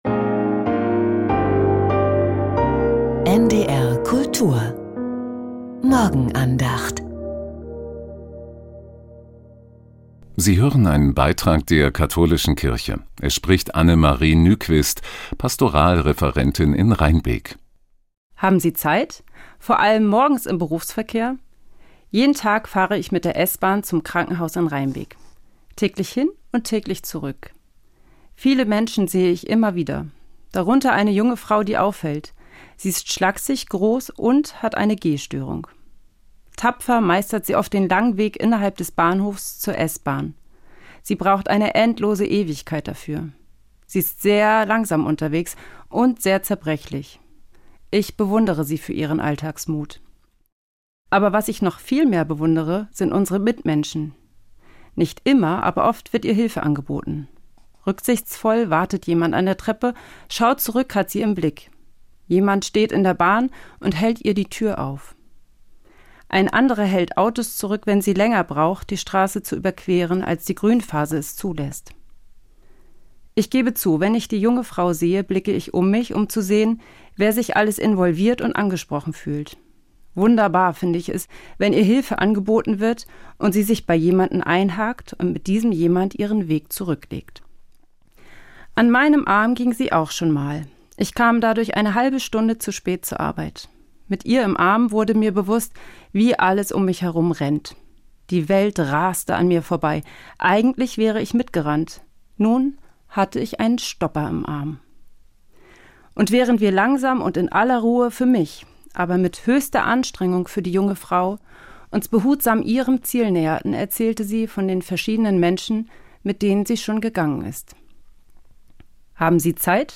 Haben Sie Zeit? ~ Die Morgenandacht bei NDR Kultur Podcast